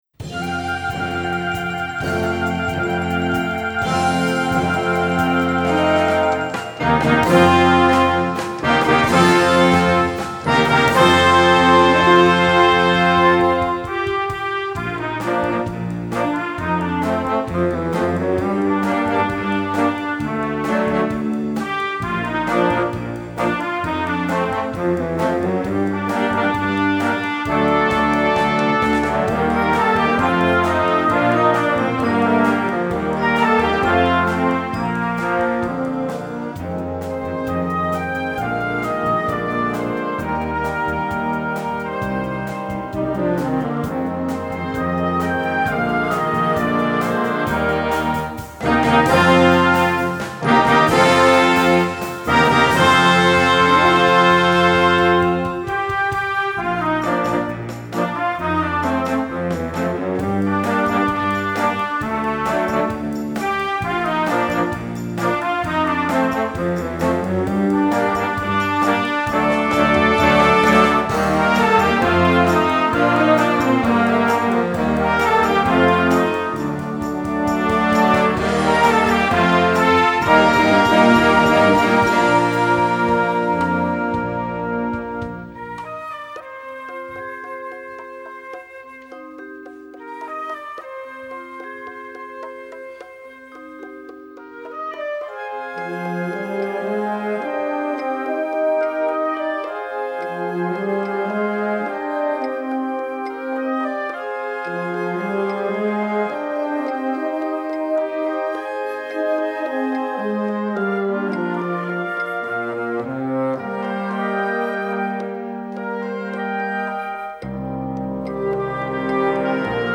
Gattung: Jugendmusik - Medley
Besetzung: Blasorchester